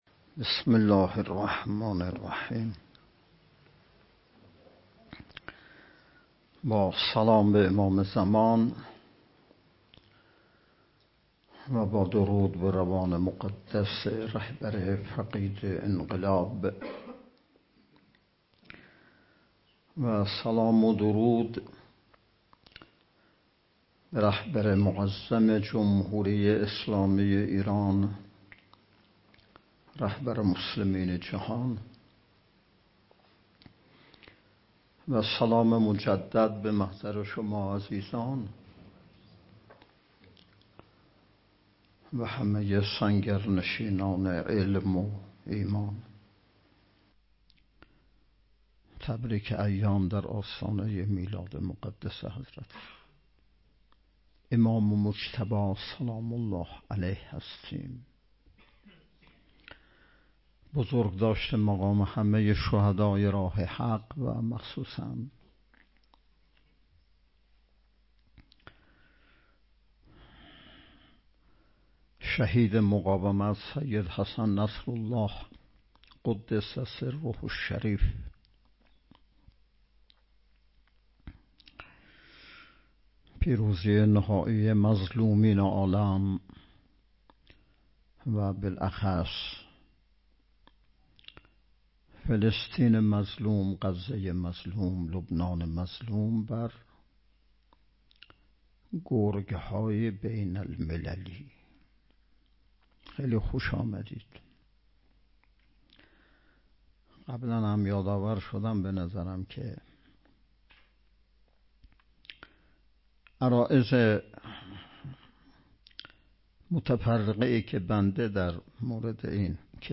پانزدهمین نشست ارکان شبکه تربیتی صالحین بسیج با موضوع تربیت جوان مؤمن انقلابی پای کار، صبح امروز ( ۱۶ بهمن) با حضور و سخنرانی نماینده ولی فقیه در استان، برگزار شد.